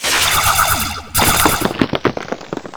laser2.wav